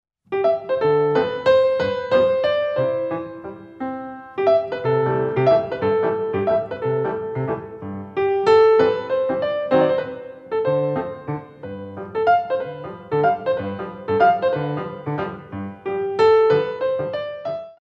Sissone Exercise